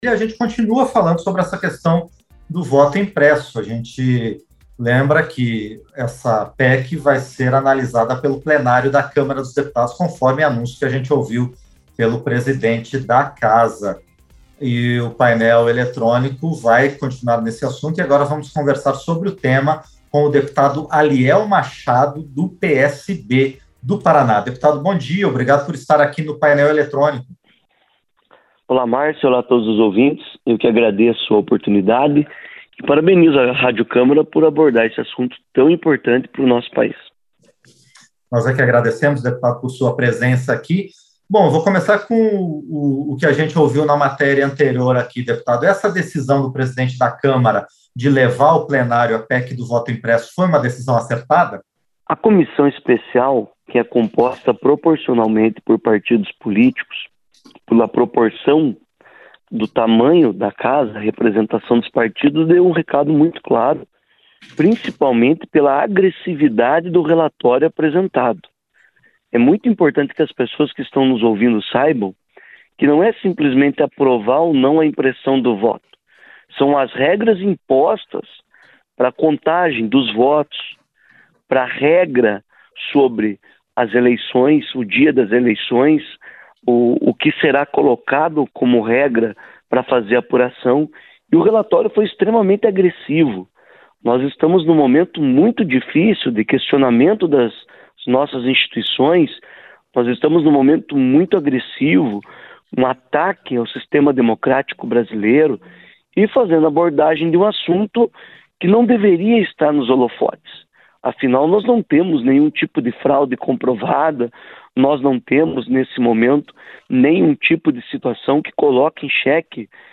Entrevista - Dep. Aliel Machado (PSB-PR)